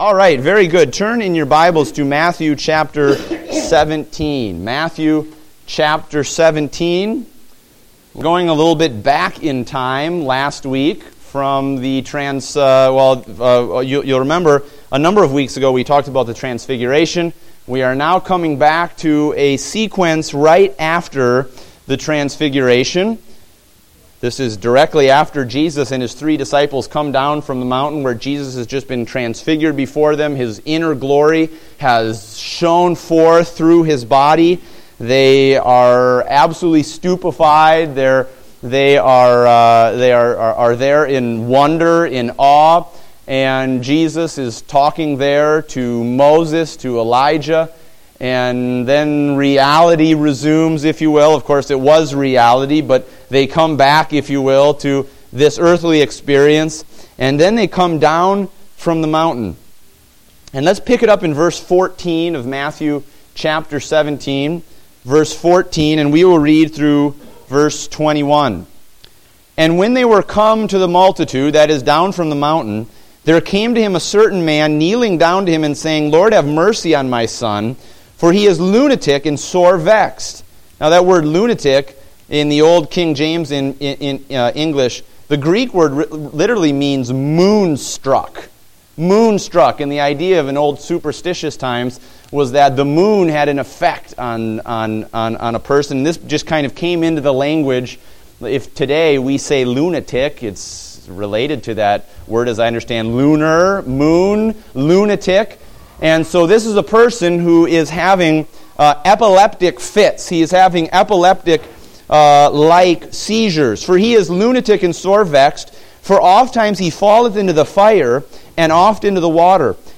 Date: February 21, 2016 (Adult Sunday School)